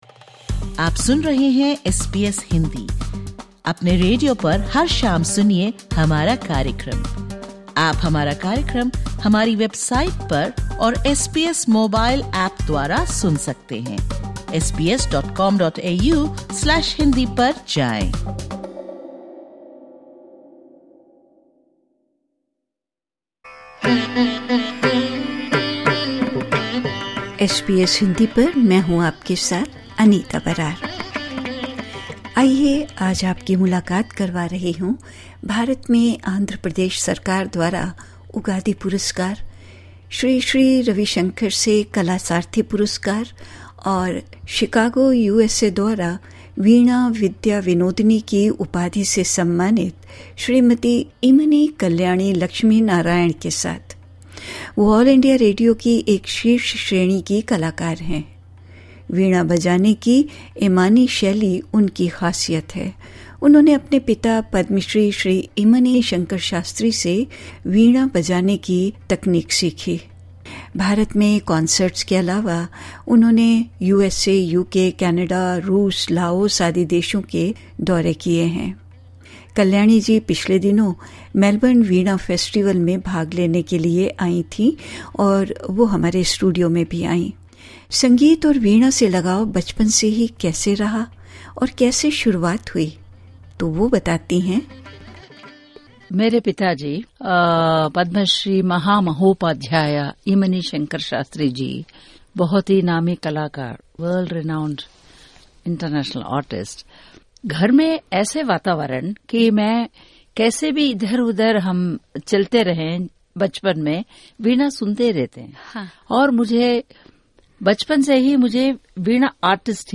इस पॉडकास्ट में सुनिये उनके साथ की गयी एक बातचीत।